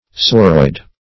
Search Result for " sauroid" : The Collaborative International Dictionary of English v.0.48: Sauroid \Sau"roid\, a. [Gr.
sauroid.mp3